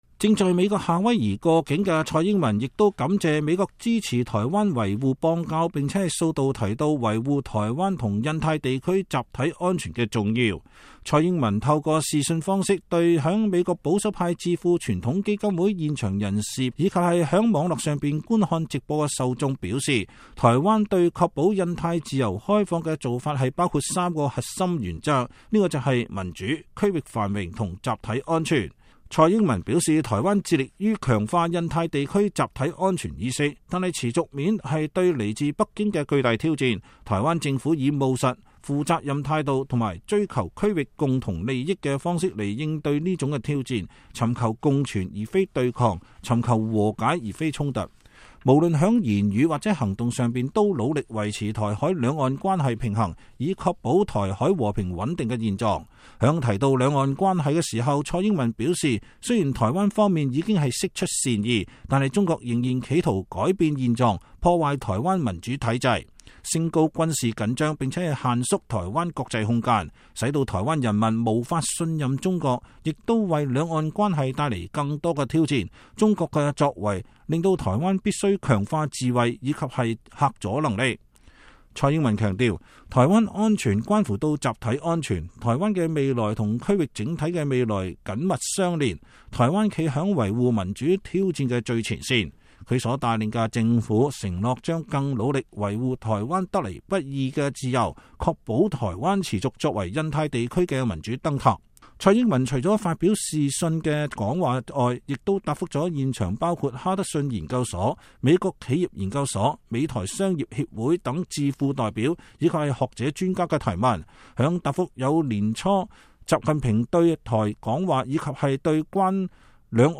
蔡英文對美智庫視訊演說 呼應特朗普自由開放印太戰略